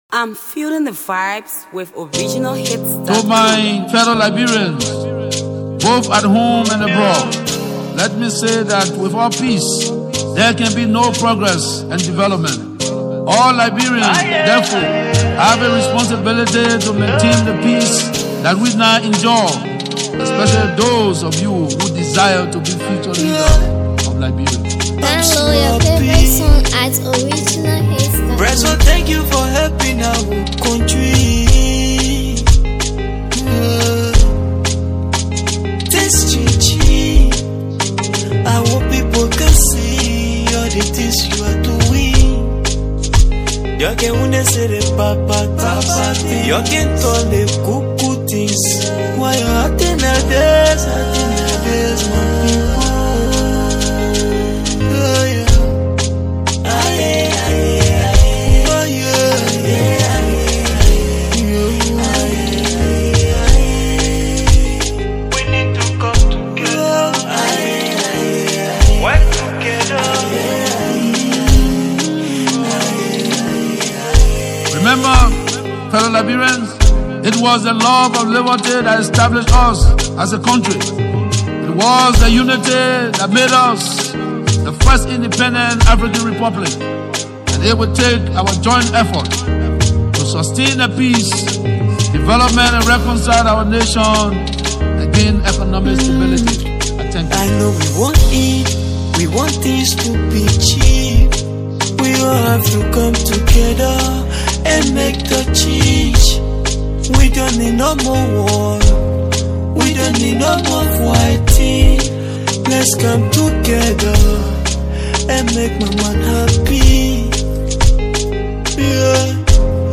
peace awareness banger